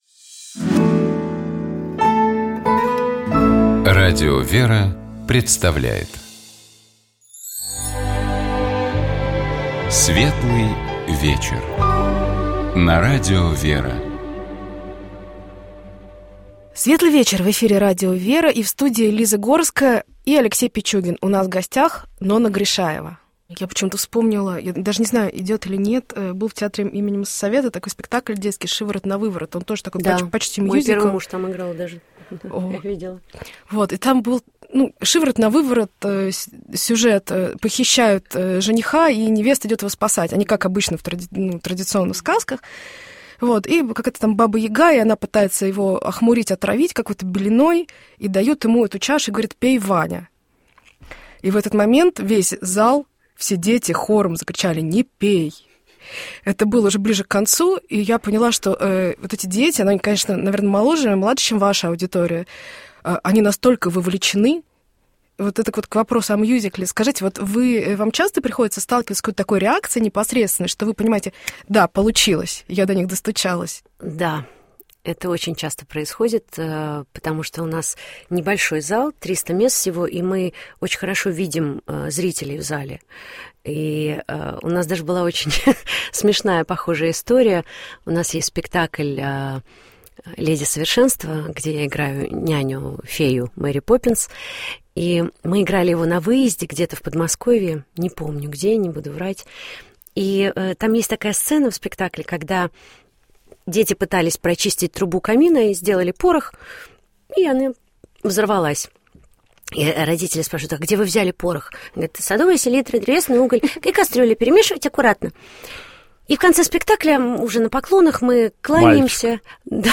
У нас в гостях была актриса театра и кино, художественный руководитель Московского областного государственного театра юного зрителя, Заслуженная артистка России Нонна Гришаева. Мы говорили о творческом пути нашей гостьи, о жизни и репертуаре возглавляемого ею театра, а также о премьере необычного спектакля «Запретный плод», повествующего о двух детях в утробе матери, родители которых размышляют над тем, чтобы сделать аборт.